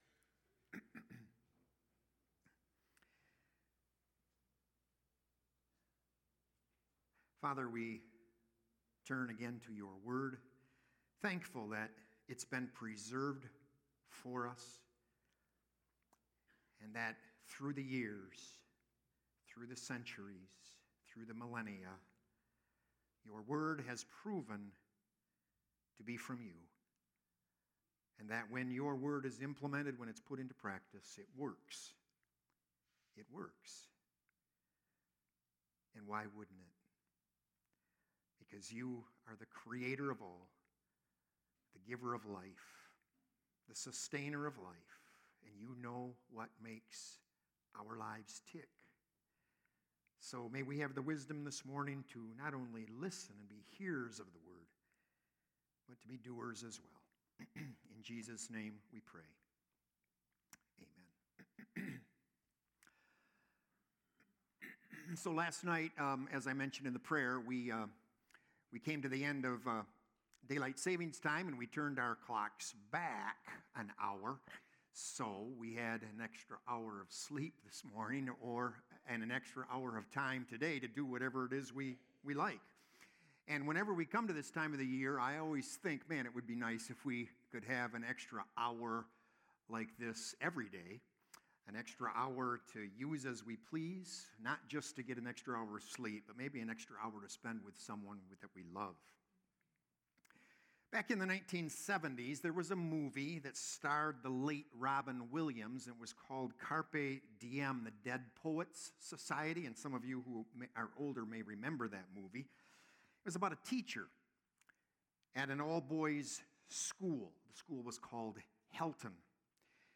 Sermons - Woodhaven Reformed Church